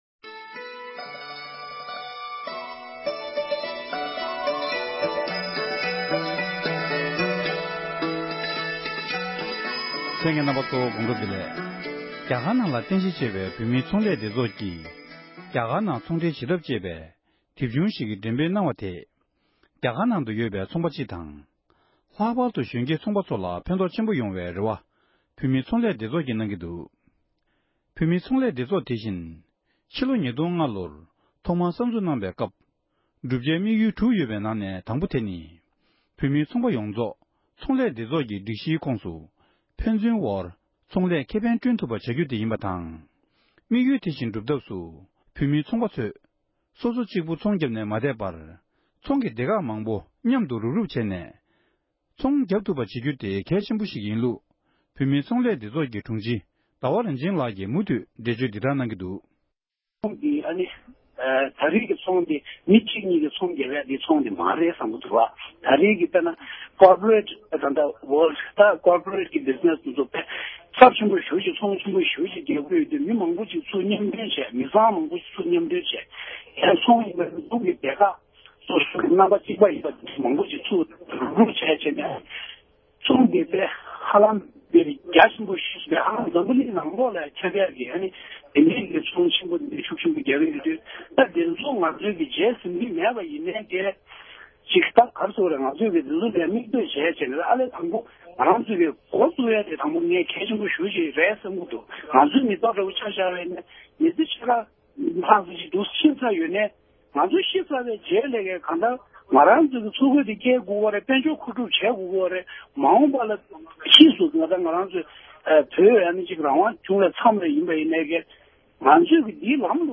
གནས་འདྲི་ཞུས་པའི་ལེ་ཚན་གྱི་དུམ་མཚམས་གཉིས་པར་གསན་རོགས༎